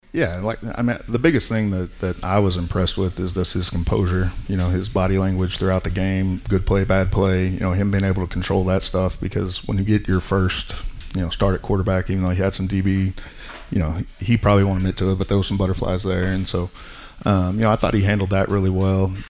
as he had his weekly radio show at Dink’s Pit BBQ.